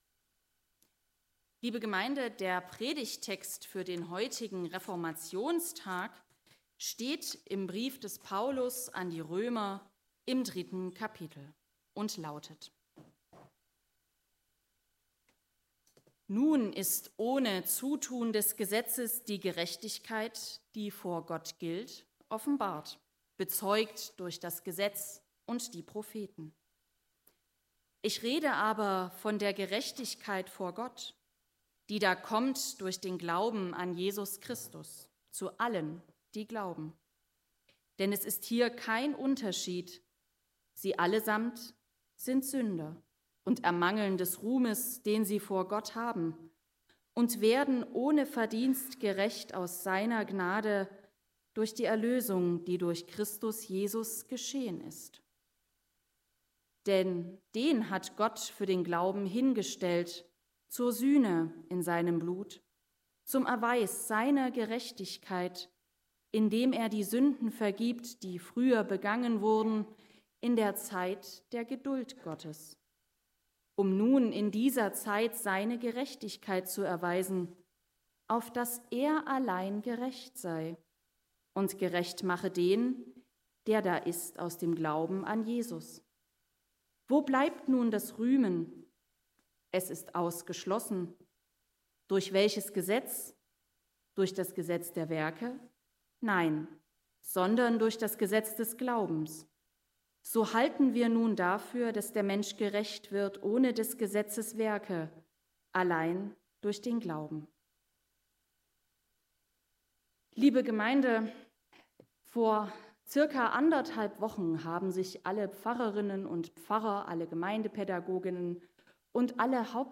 Römer 3 Gottesdienstart: Gemeinsamer Gottesdienst Lasst uns über Bürokratie reden!